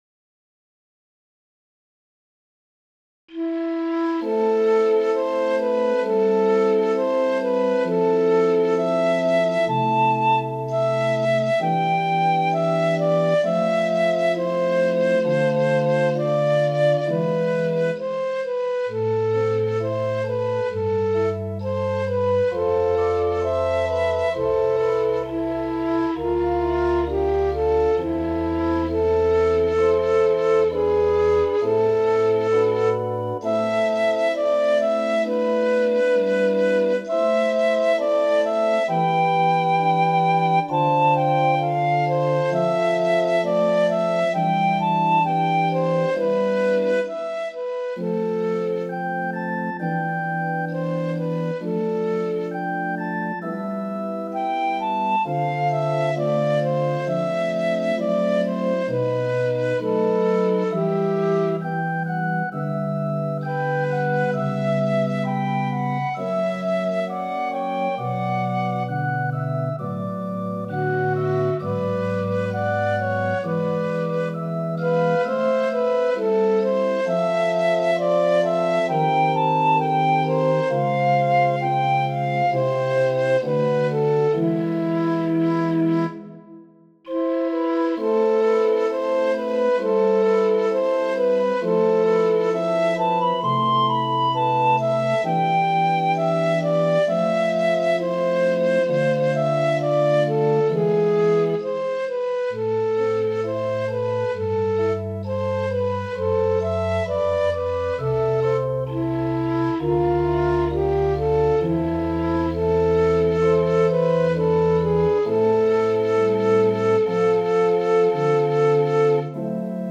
Ecabe – Petite pièce pour flûte de Pan ou autre instrument (Ut ou Sib), et orgue.